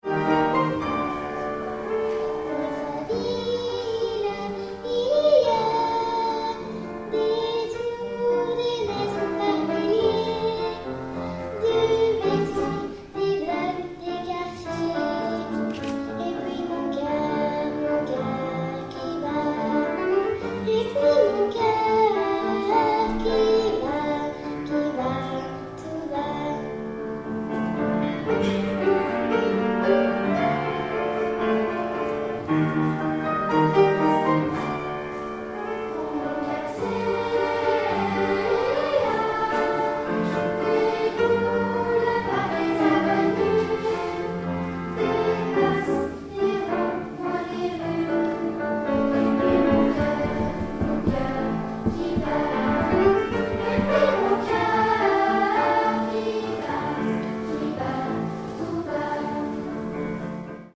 2009 - 2010 - Choeur d'enfants La Voix du Gibloux